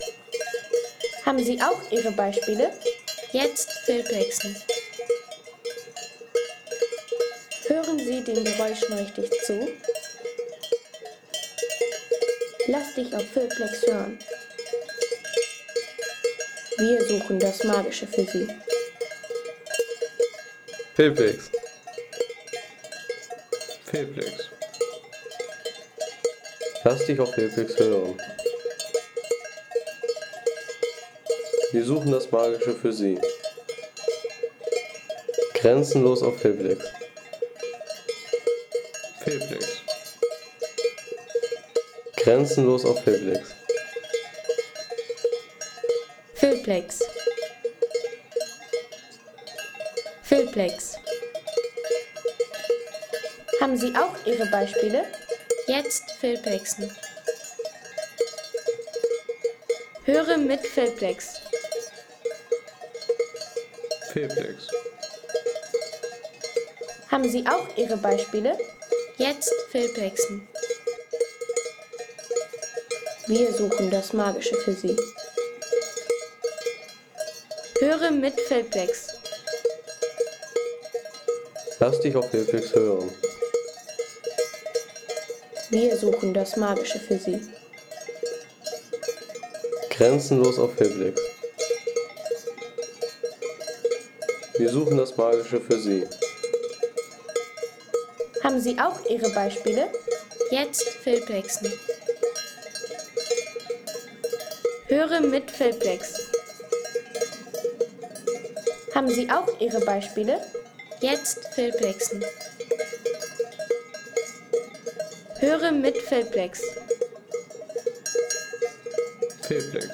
Klang der Bergwiesen
Klang der Bergwiesen Home Sounds Tierwelt Bauernhof-Tiere Klang der Bergwiesen Seien Sie der Erste, der dieses Produkt bewertet Artikelnummer: 145 Kategorien: Tierwelt - Bauernhof-Tiere Klang der Bergwiesen Lade Sound....